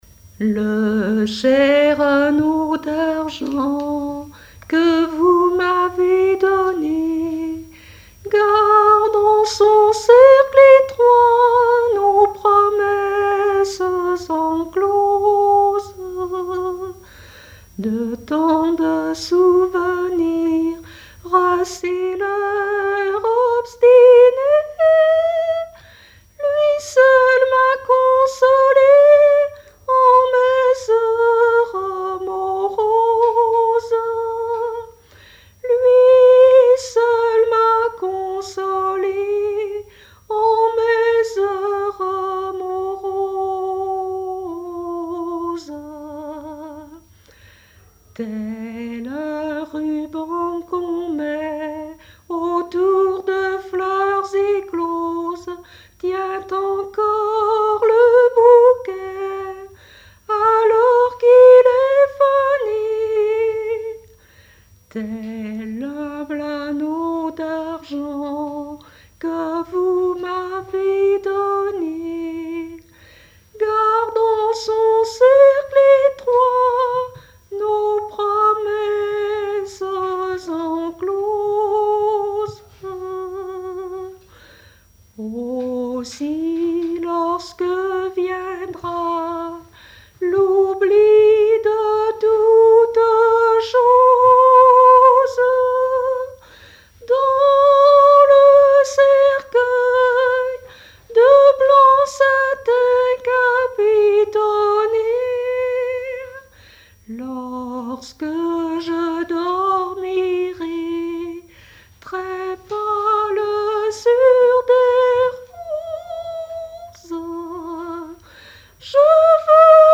regroupement de chanteurs de la commune
Pièce musicale inédite